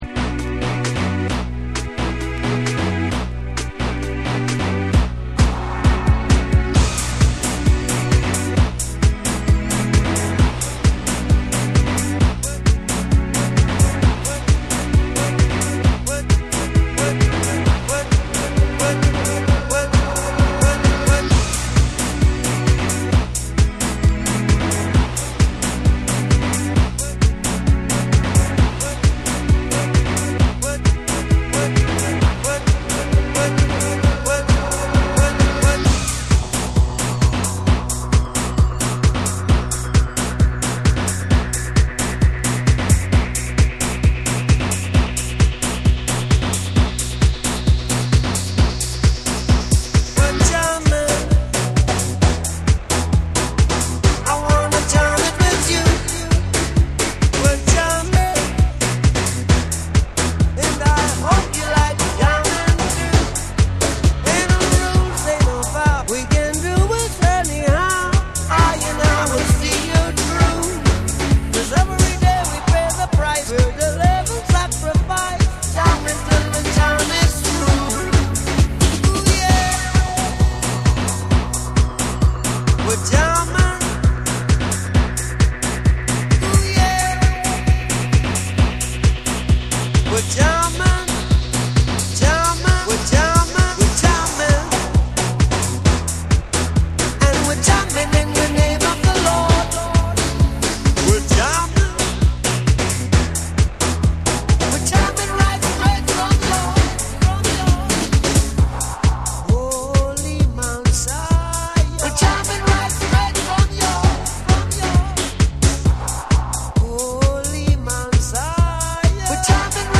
フロア映えするダンス・ナンバーにリミックス！
REGGAE & DUB / TECHNO & HOUSE